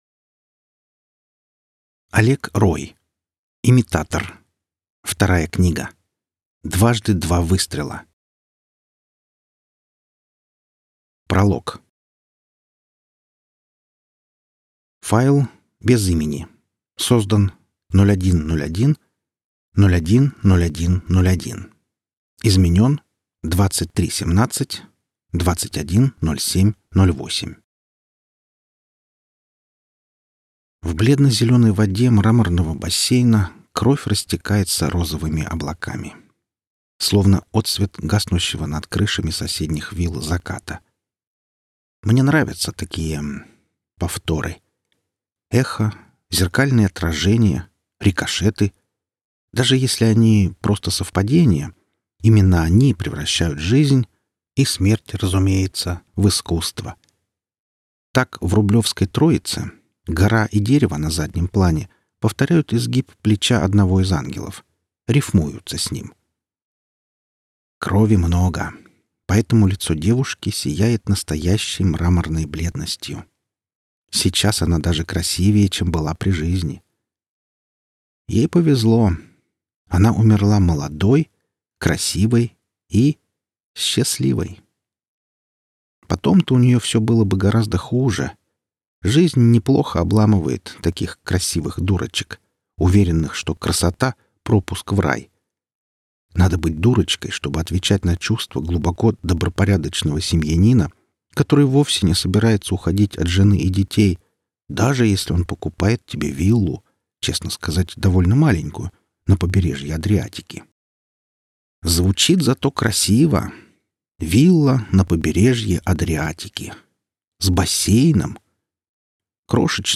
Аудиокнига Имитатор. Книга вторая. Дважды два выстрела | Библиотека аудиокниг